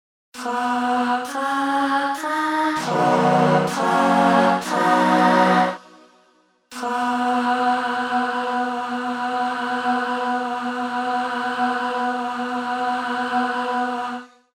Vlt. hilft dir dieses Vox Sample weiter ? your_browser_is_not_able_to_play_this_audio Ansonsten würde ich das auch eher über eine Background-Sängerin einsingen lassen und ggfls. diese Passagen sampeln.